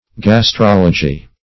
Search Result for " gastrology" : The Collaborative International Dictionary of English v.0.48: Gastrology \Gas*trol"o*gy\, n. [Gr ?; ?, ?, stomach + ? discourse: cf. F. gastrologie.]
gastrology.mp3